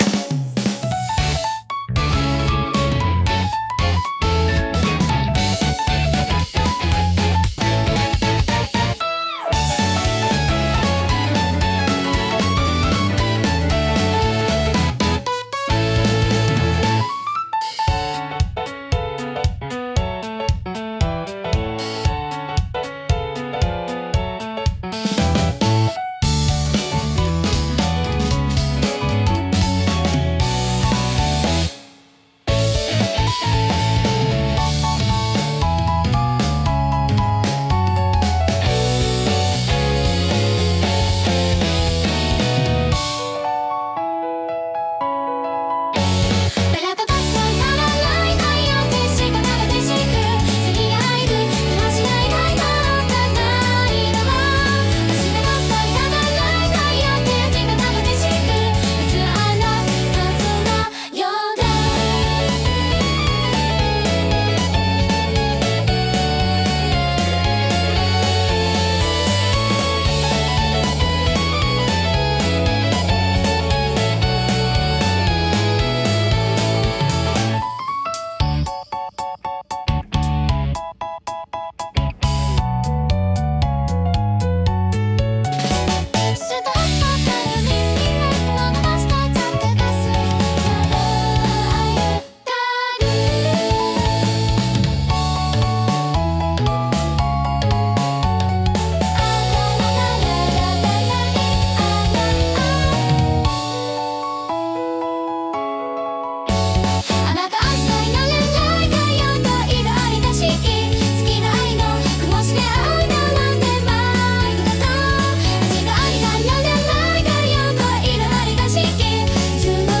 キュートコアは、明るくポップで可愛らしいメロディとエネルギッシュなビートが融合したオリジナルジャンルです。
キャッチーなフレーズと元気なリズムが特徴で、聴く人に楽しい気分を与えます。
親しみやすく軽快な空気感を演出したい場面で活躍します。